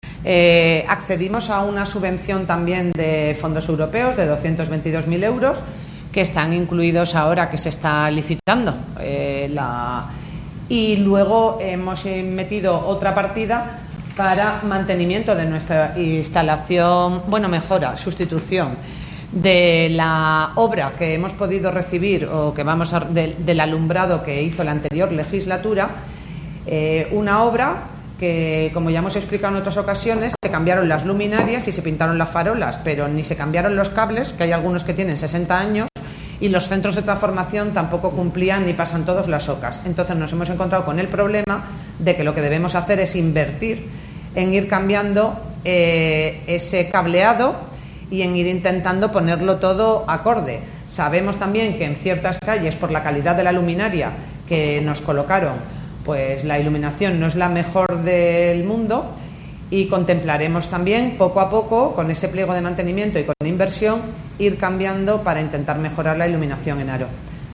Lo ha adelantado la alcaldesa Guadalupe Fernández a preguntas de RADIO HARO.